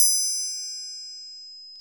Index of /90_sSampleCDs/Roland - Rhythm Section/PRC_Latin 2/PRC_Triangles
PRC TRIANG09.wav